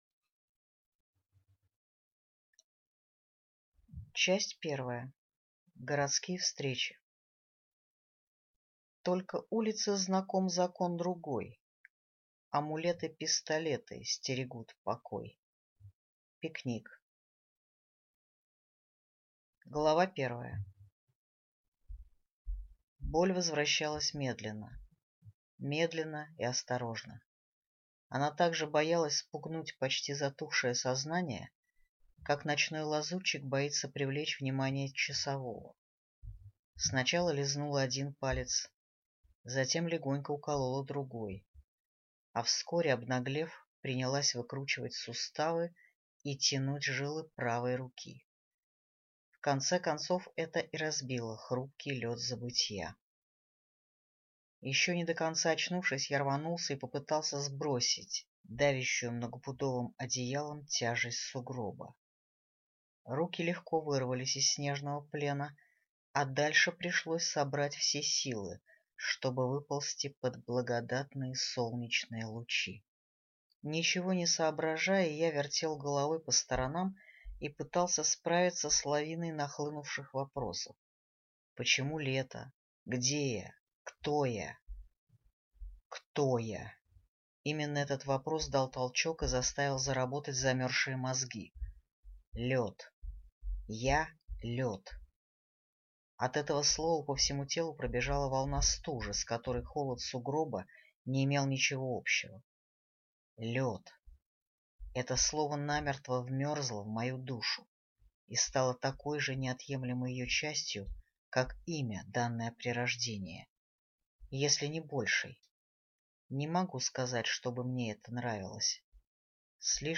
Аудиокнига Скользкий | Библиотека аудиокниг